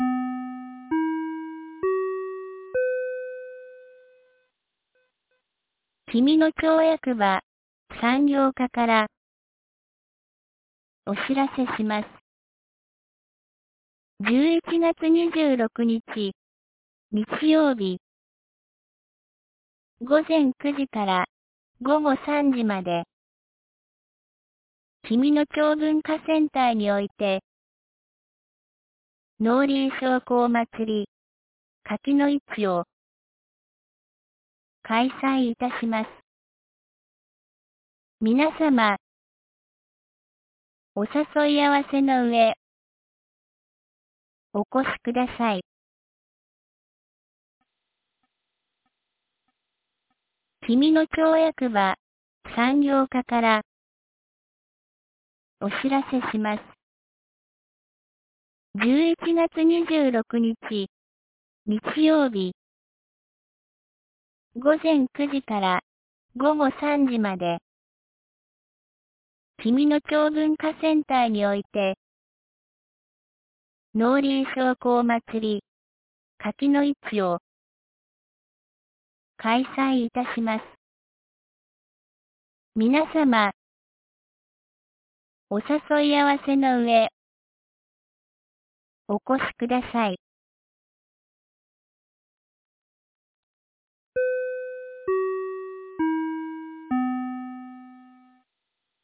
2023年11月25日 17時06分に、紀美野町より全地区へ放送がありました。